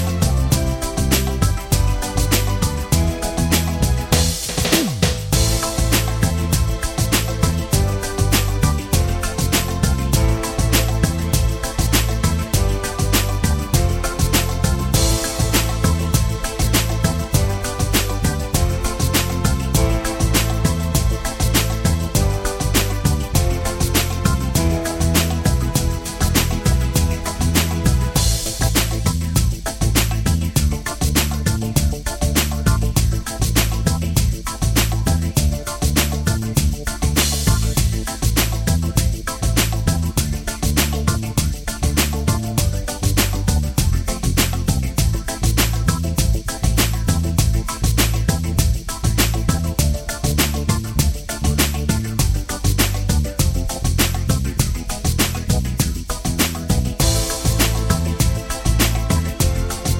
Minus Lead Guitar For Guitarists 3:41 Buy £1.50